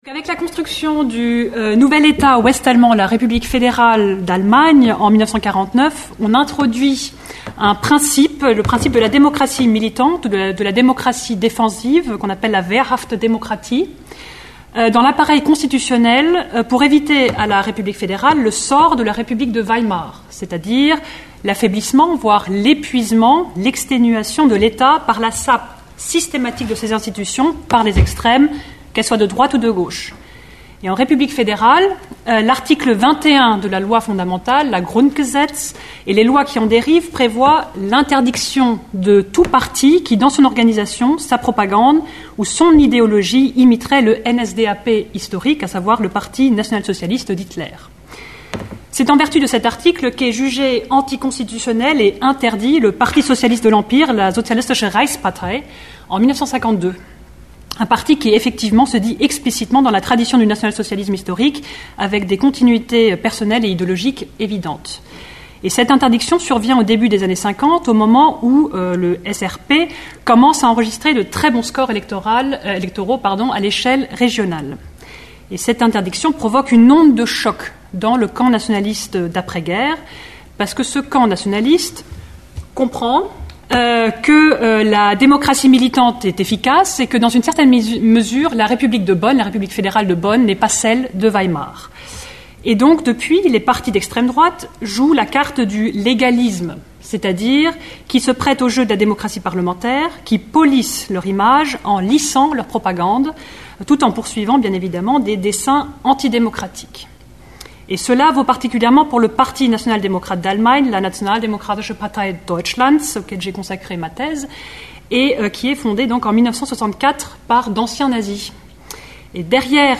Cette intervention à deux voix se propose de dresser un bref état des lieux des évolutions contemporaines de la propagande d’extrême-droite en Allemagne. Sont évoqués la situation juridique et politique en Allemagne, les différents sujets de campagne du NPD (parti national-démocrate d’Allemagne) et des autres mouvances d’extrême-droite, le rôle des femmes et des médias sociaux, et la place singulière occupée de la musique.